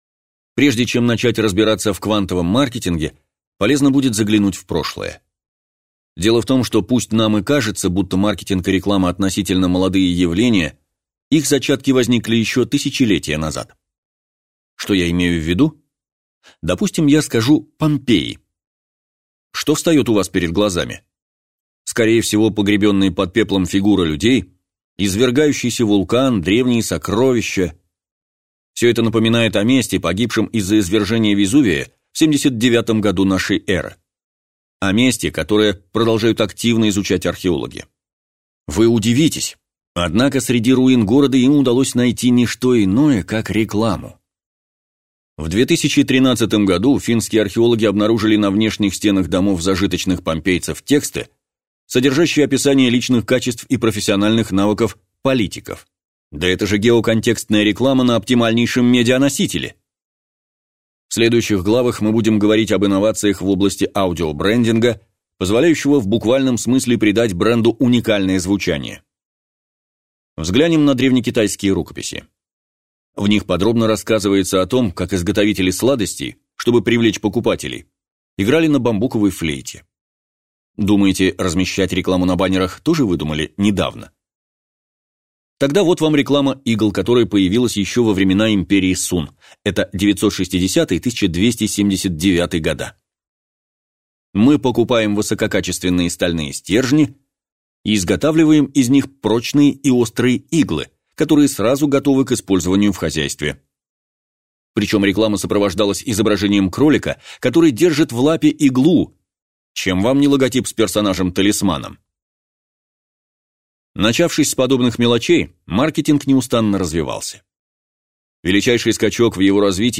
Аудиокнига Квантовый скачок маркетинга. Если не внедрите это сегодня, вашей компании не станет завтра | Библиотека аудиокниг